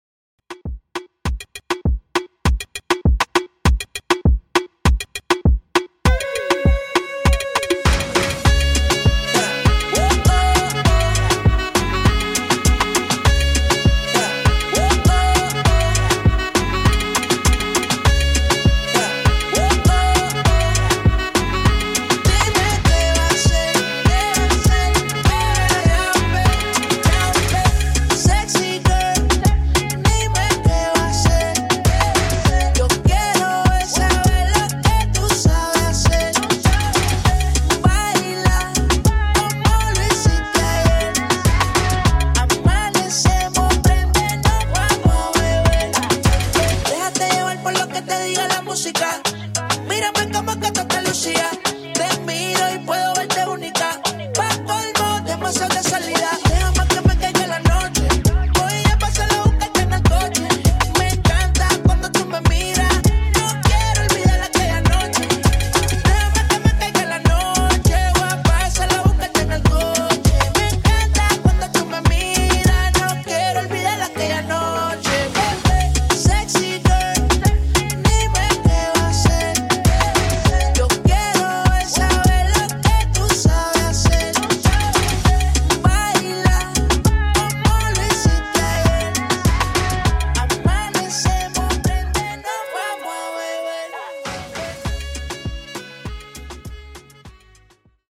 Afrobeat Hall)Date Added